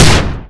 rifle_pri_firev8.wav